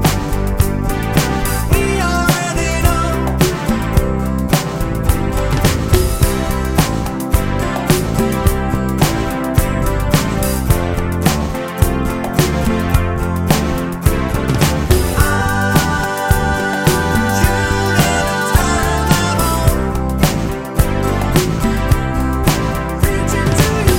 No Piano Pop (1970s) 4:18 Buy £1.50